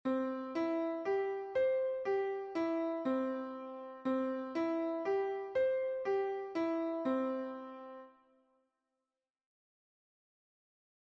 Pero antes, a ver que tal vas de oído, relacionando os seguintes audios coas partituras que se che ofrecen, un ditado melódico cun propósito real.
Dictado_1_do-mi-sol-do-sol-mi-do.mp3